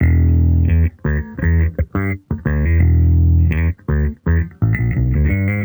Index of /musicradar/sampled-funk-soul-samples/85bpm/Bass
SSF_JBassProc2_85E.wav